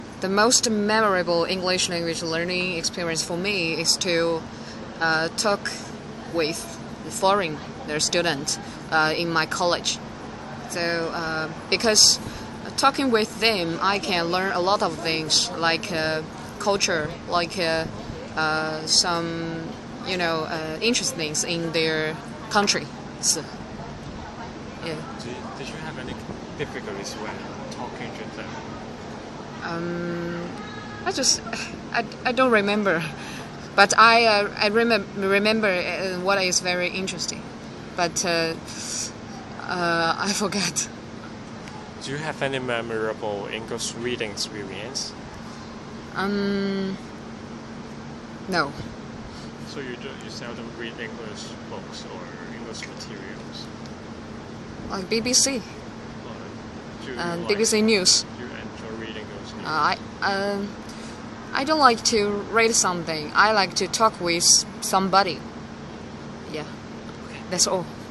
Subcategory: Internet, Journalism, Reading, Speech